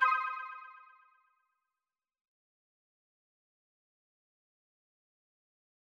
confirm_style_4_echo_002.wav